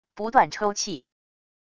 不断抽泣wav音频